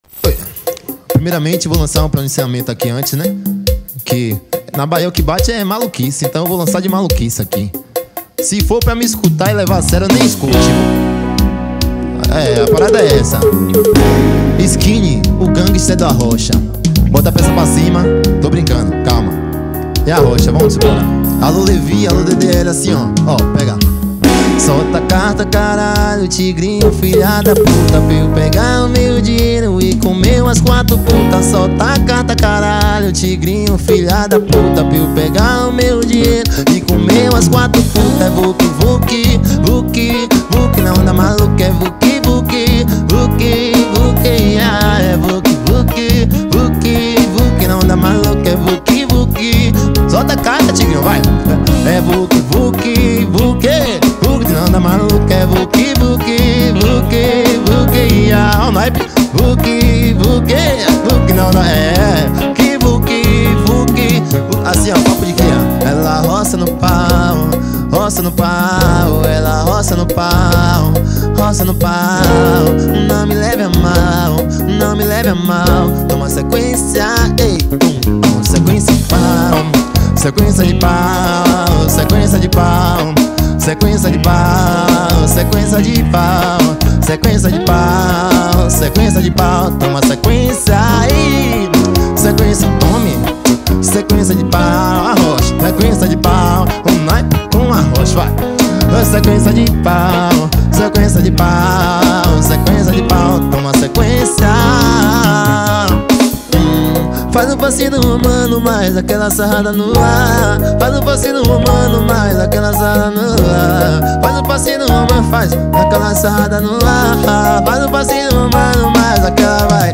2025-01-26 20:41:19 Gênero: Arrocha Views